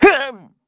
Evan-Vox_Damage_kr_06.wav